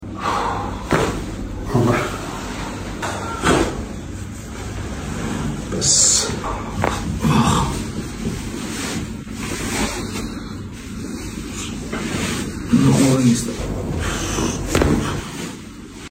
Cracks & Crunchy !